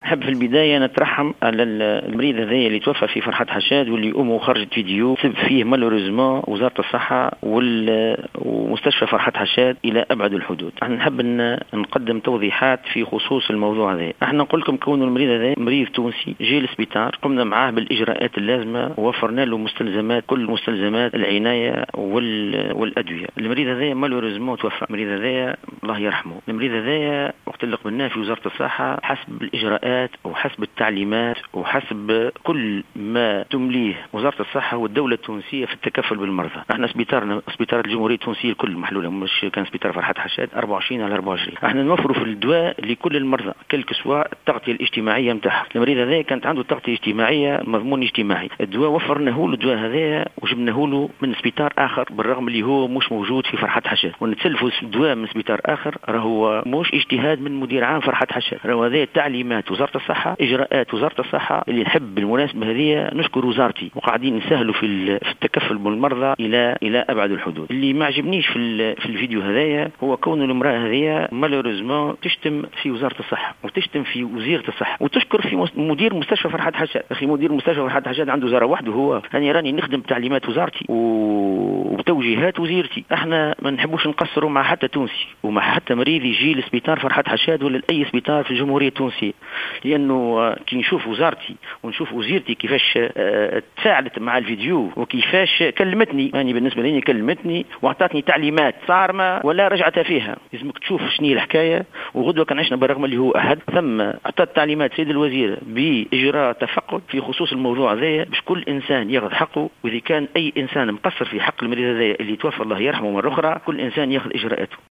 في تصريح لـ "الجوهرة اف ام"